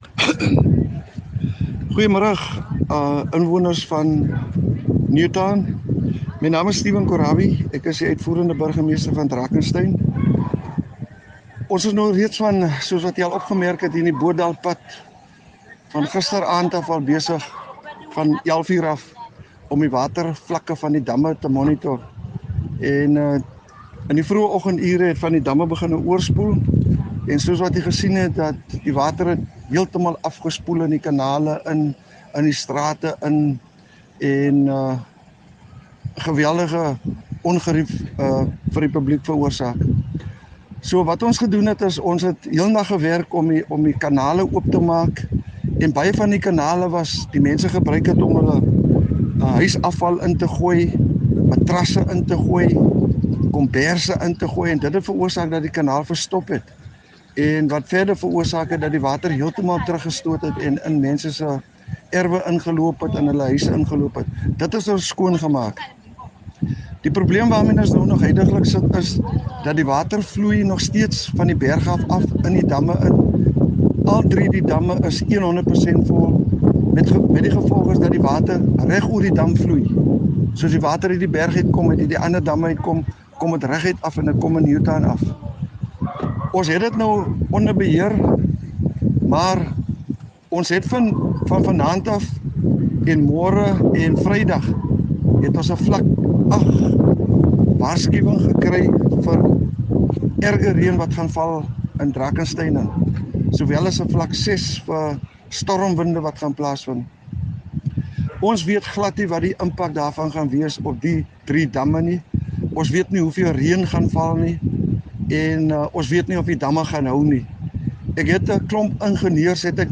10 Jul Executive Mayor Steven Korabie message to Drakenstein residents